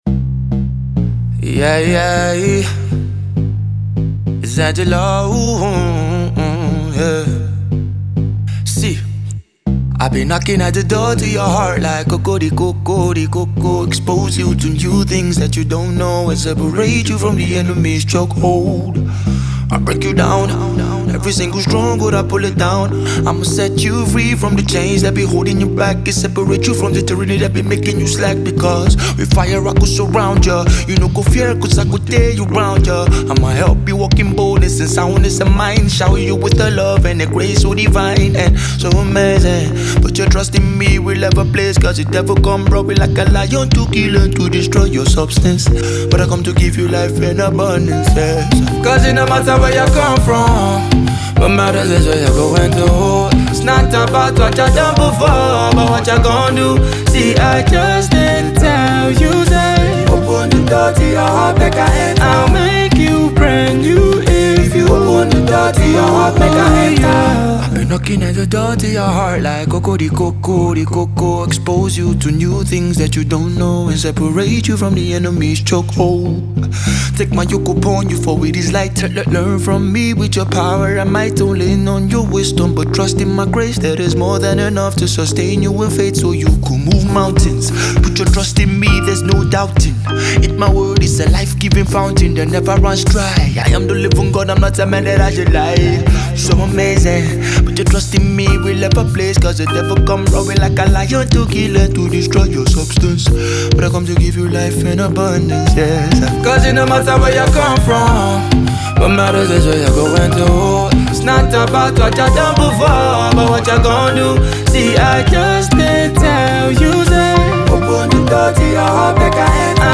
soulful dance-hall tune